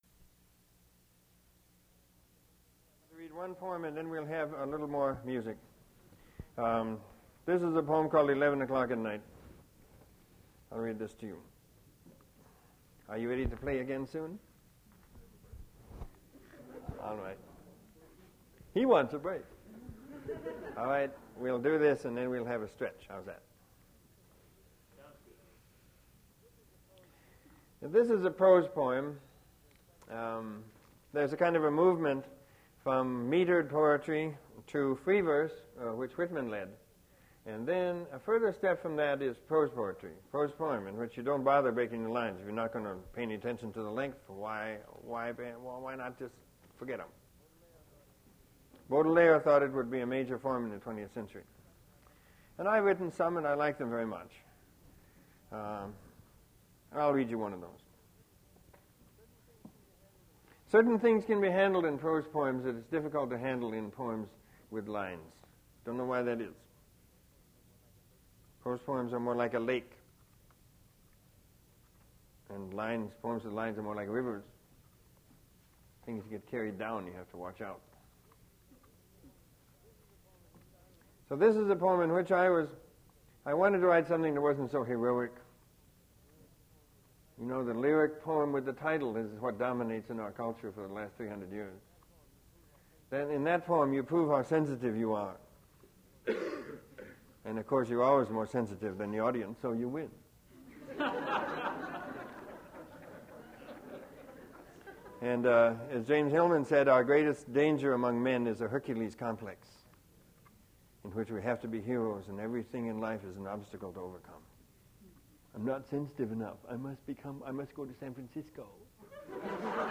Robert Bly poetry reading at Duff's Restaurant for River Styx PM Series (continued)
Finding the Father (with music) 23:14; Finding the Father (without music) 25:40
mp3 edited access file was created from unedited access file which was sourced from preservation WAV file that was generated from original audio cassette.
recording cuts off during last poem